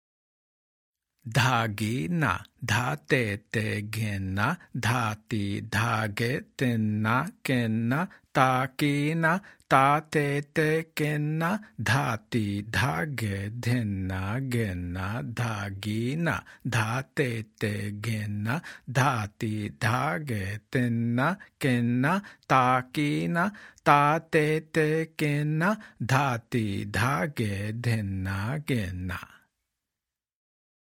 Demonstrations
Spoken